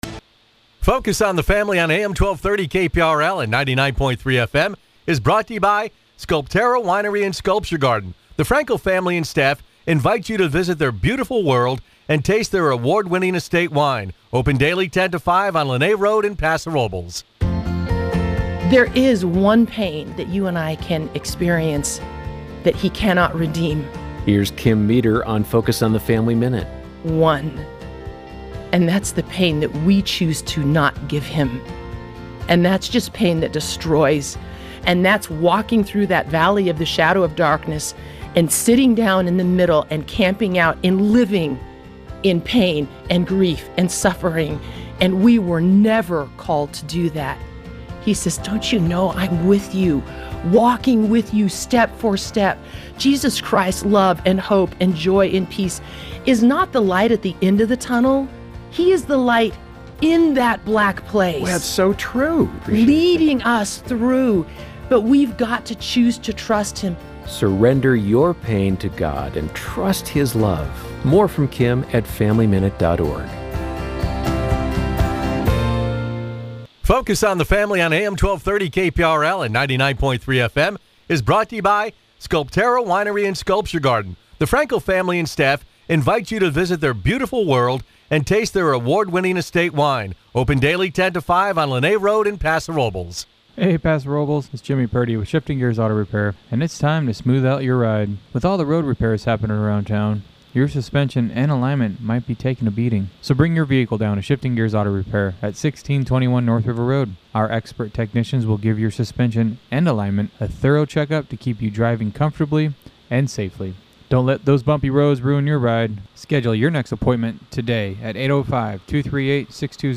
Tune in to the longest running talk show on the Central Coast – now in its sixth decade.